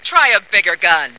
flak_m/sounds/female2/int/F2biggergun.ogg at 86e4571f7d968cc283817f5db8ed1df173ad3393